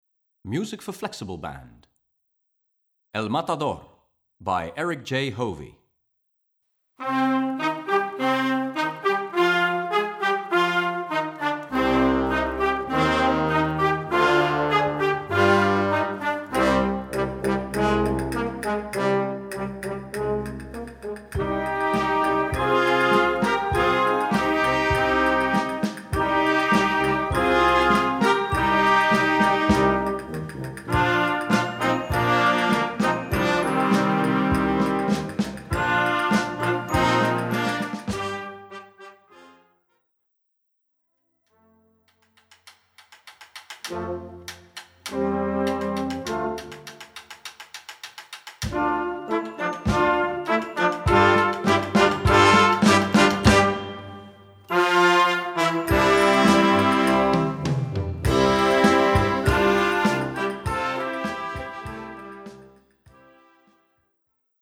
Gattung: Jugendwerk
Besetzung: Blasorchester
im Stil eines spanischen Paso doble komponiert